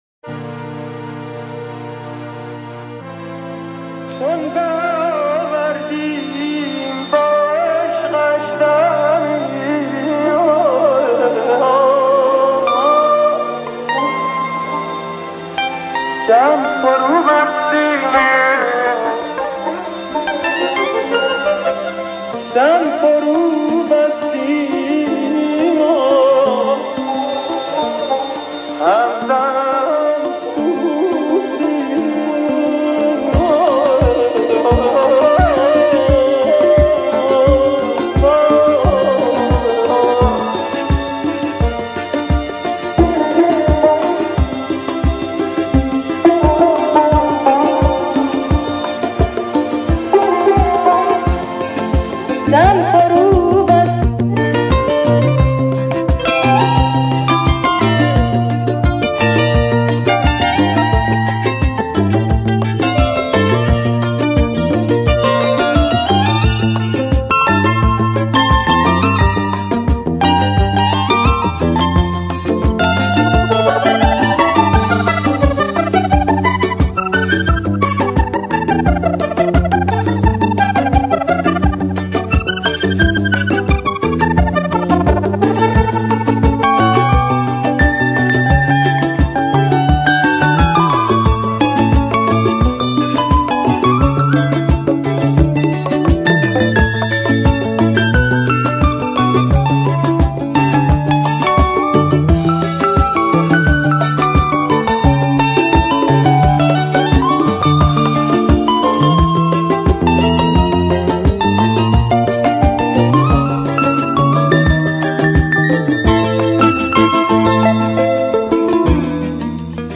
Petit zouk de love